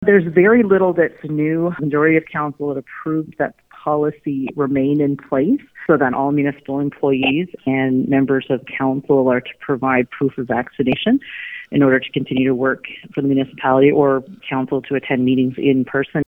That’s Mayor Christa Lowry, who says as far as the Town is concerned, “fully vaccinated” is still the ability to prove two injections.